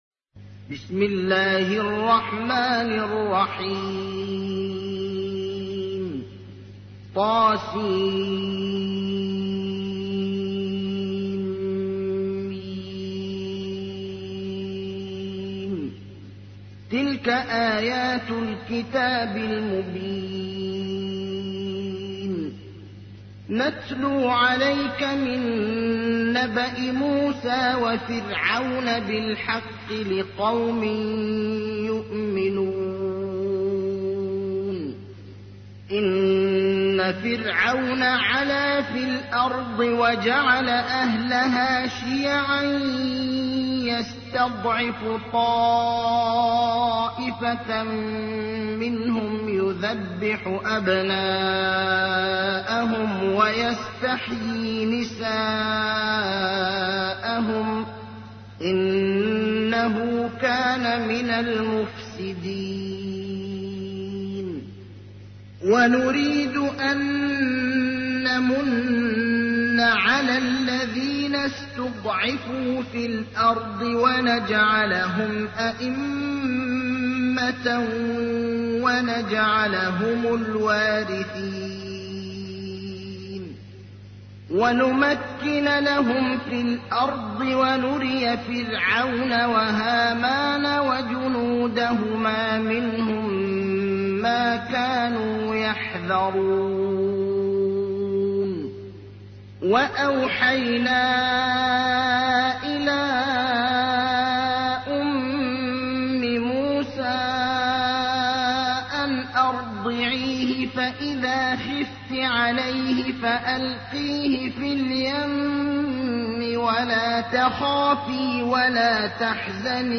تحميل : 28. سورة القصص / القارئ ابراهيم الأخضر / القرآن الكريم / موقع يا حسين